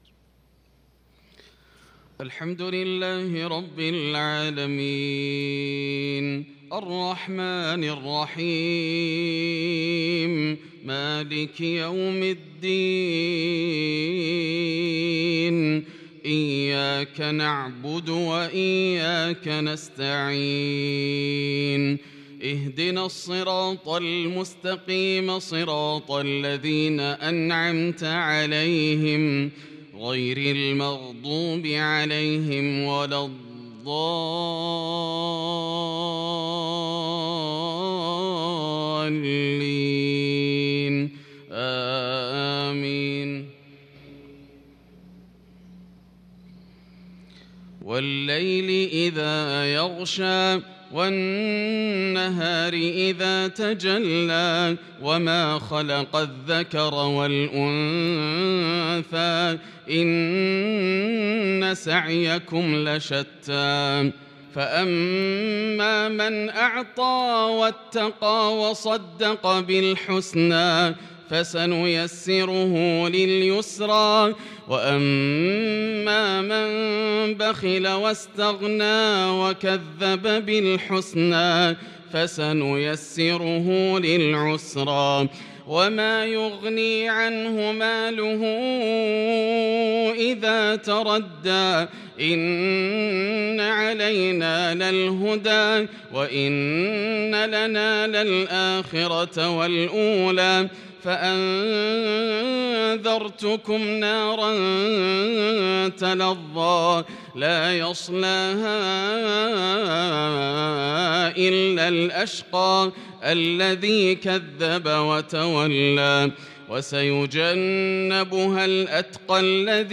صلاة المغرب للقارئ ياسر الدوسري 14 صفر 1443 هـ
تِلَاوَات الْحَرَمَيْن .